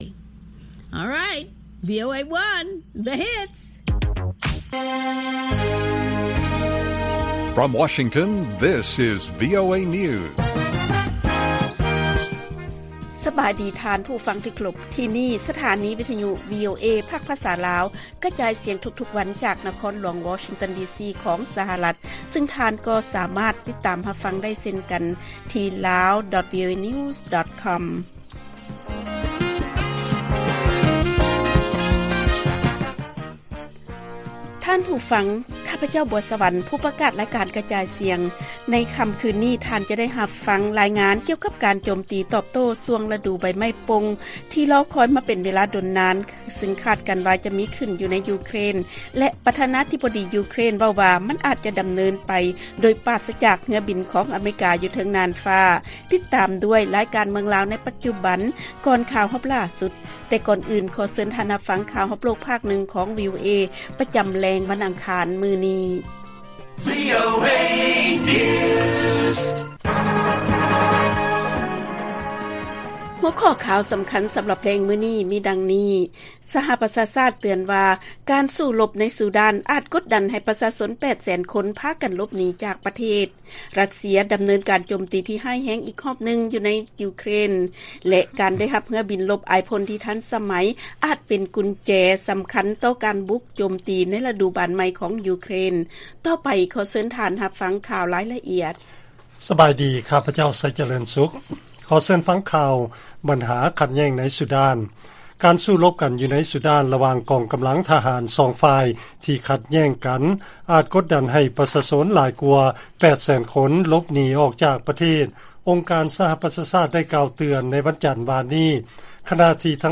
ລາຍການກະຈາຍສຽງຂອງວີໂອເອ ລາວ: ສປຊ ວ່າ ການສູ້ລົບໃນຊູດານ ອາດກົດດັນ ໃຫ້ປະຊາຊົນ 800,000 ຄົນພາກັນຫຼົບໜີຈາກປະເທດ